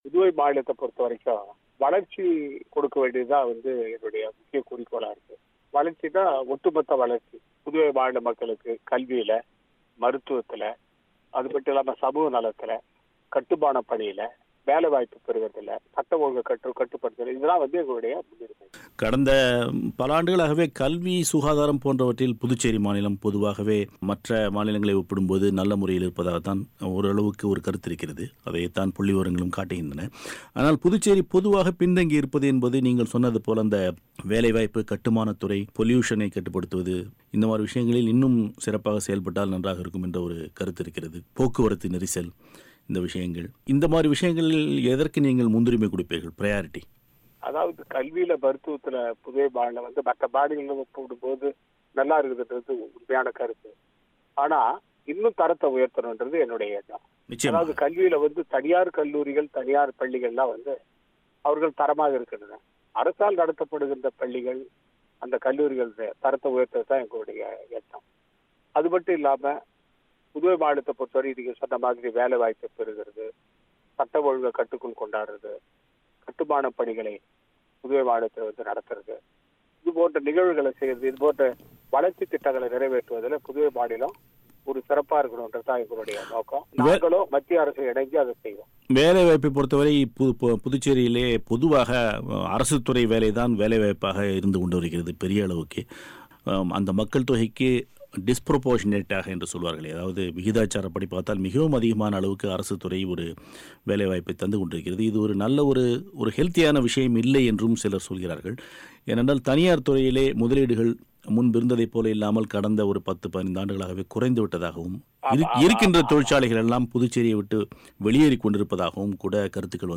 பிரத்யேக பேட்டியை நேயர்கள் கேட்கலாம்.